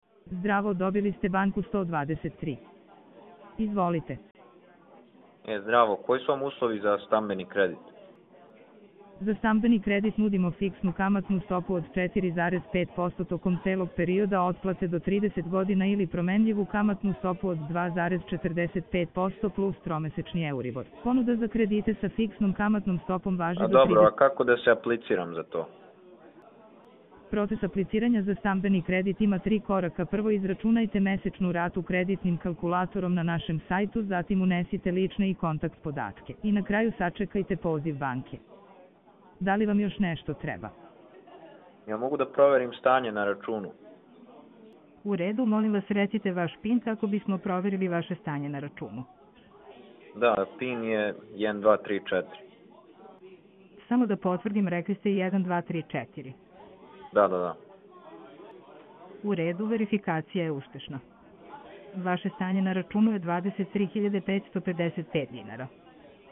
Ovo su demonstracije stvarnih scenarija u kojima AI agent razgovara kao pravi operater.